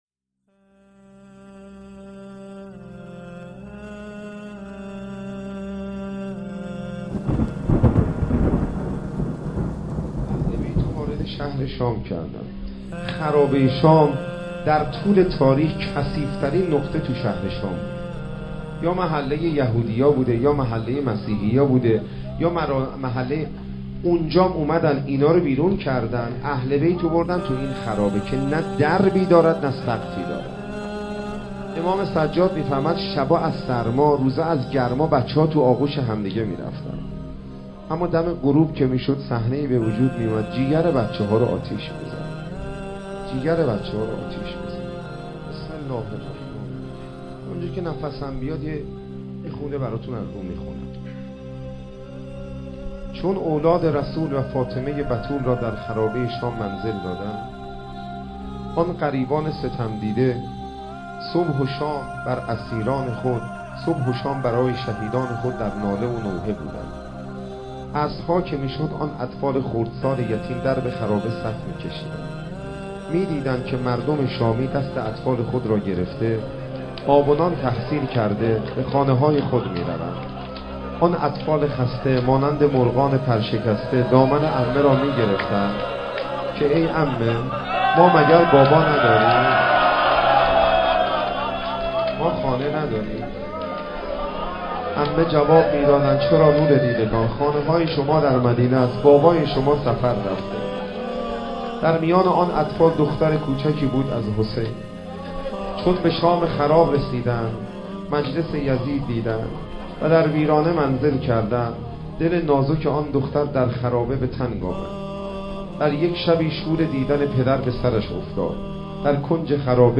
مقتل خوانی شهادت حضرت رقیه سلام الله علیها